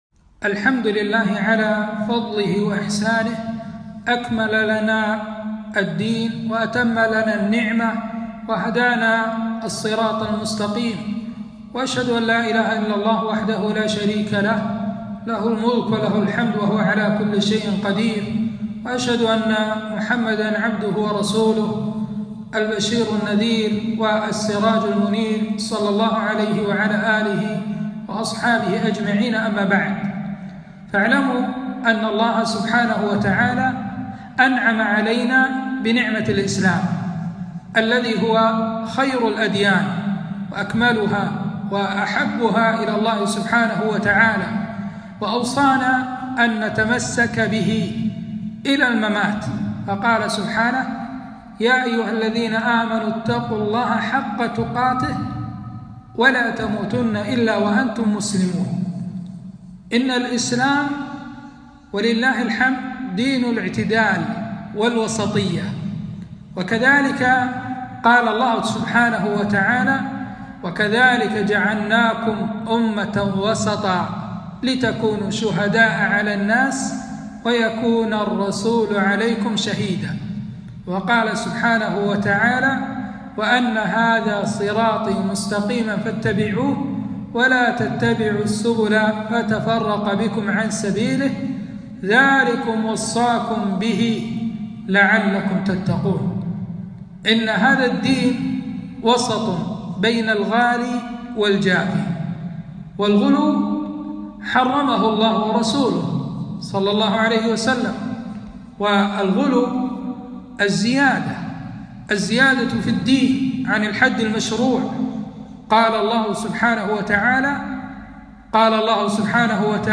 محاضرة - التحذير من الغلو في الدين